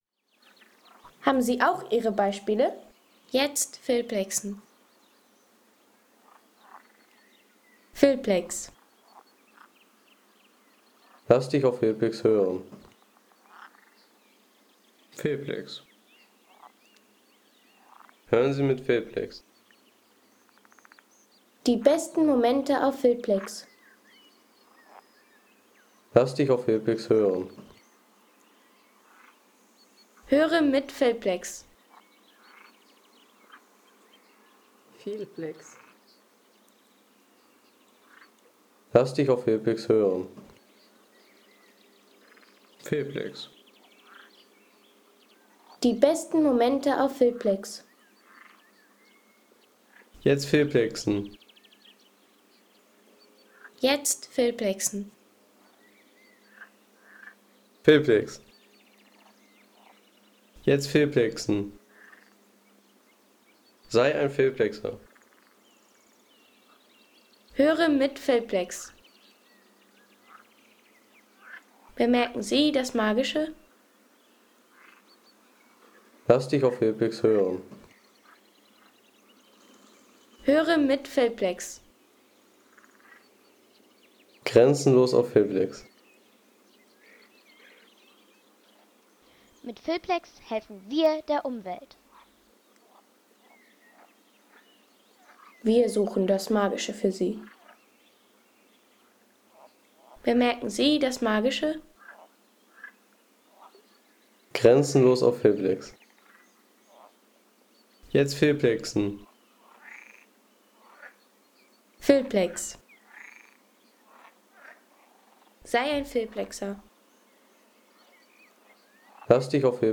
Der sommerlicher Frühling am Winderatter See – Naturschutzgebiet.